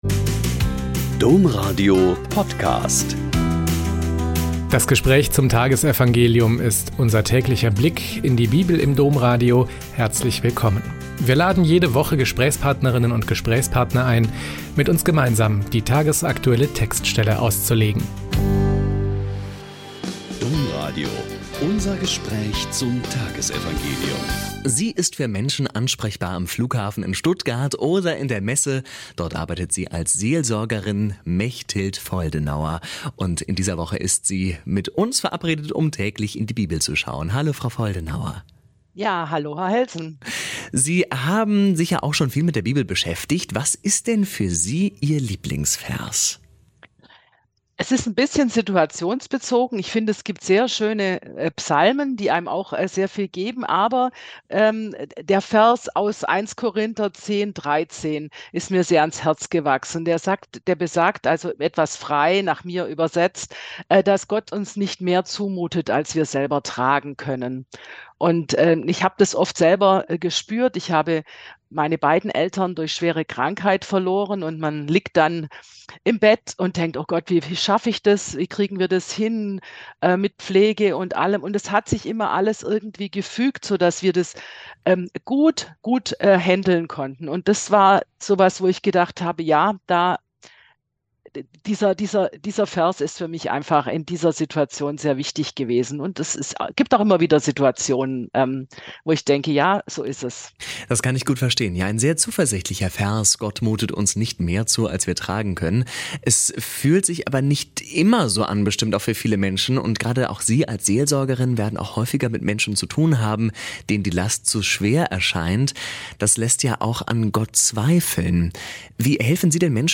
Mt 19,3-12 - Gespräch